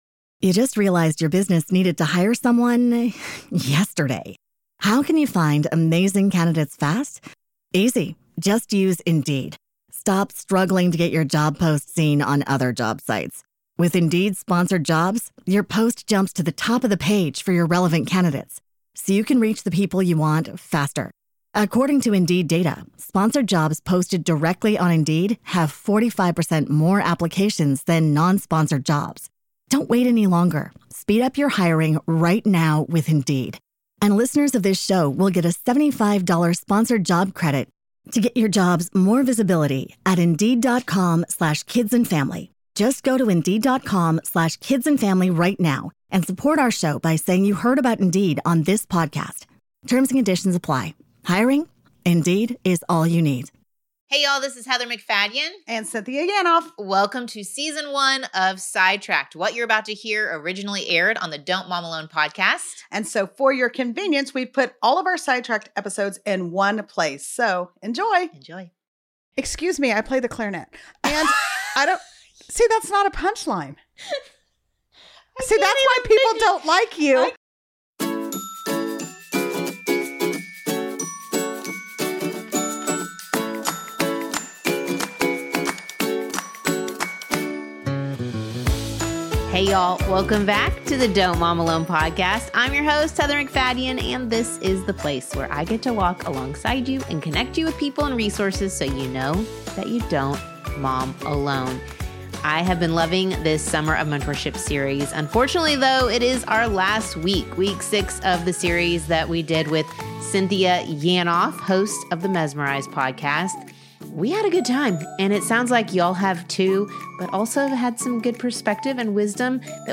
We’ll answer some listener questions, share our experiences, and laugh a lot.